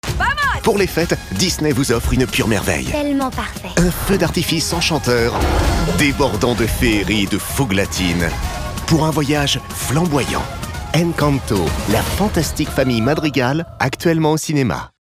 Prestation voix-off souriante et dynamique pour "Encanto, la fantastique famille Madrigal"
Sourire, magie et enthousiasme.
Pour « Encanto, la fantastique famille Madrigal », j’ai utilisé une hauteur de voix médium, afin de capturer l’essence de ce film d’animation merveilleux. Ma voix se voulait souriante, dynamique, pour enfant et punchy, afin de véhiculer l’énergie et la joie qui émanent de cette aventure cinématographique.